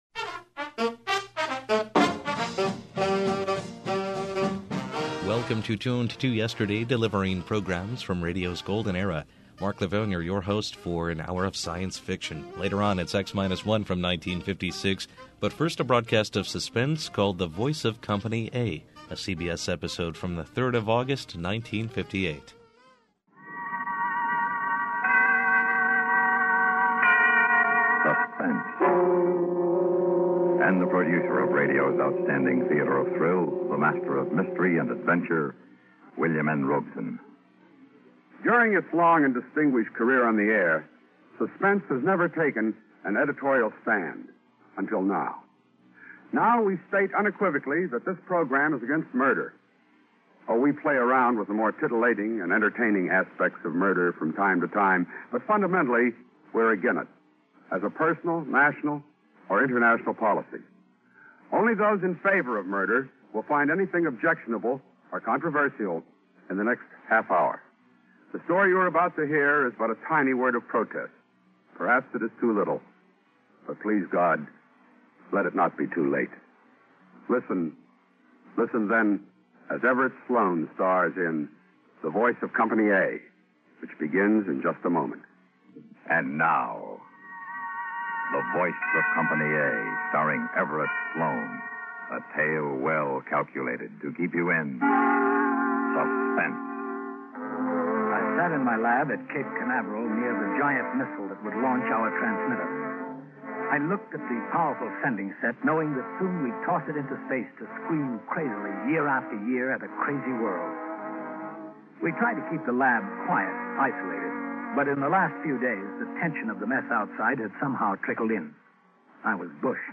Classic Radio
Audio Drama